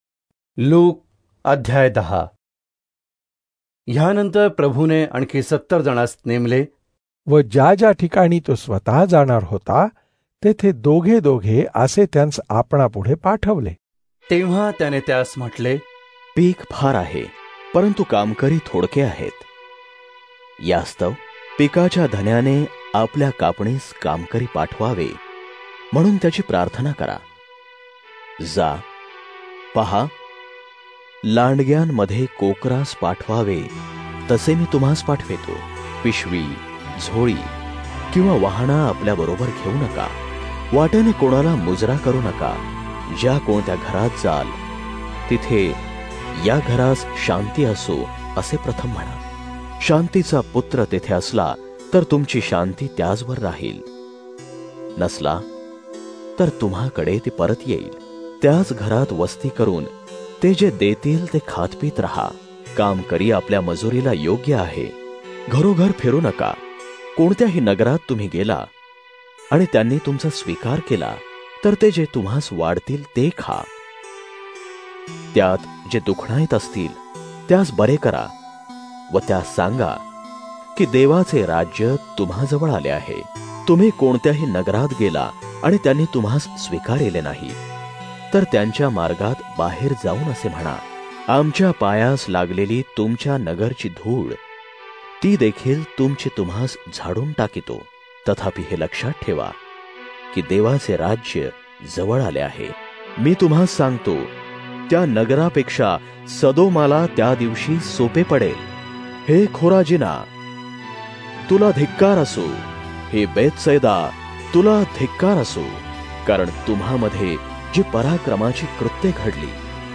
Marathi Audio Drama Bible New Testament - United Evangelical Christian Fellowship(UECF), New Jersey - Popular Christian Website Telugu Hindi Tamil Malayalam Indian Christian audio Songs and daily bible devotions